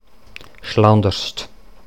Deutsch-mundartliche Form
[ˈʃlɔndərʃt]
Schlanders_Mundart.mp3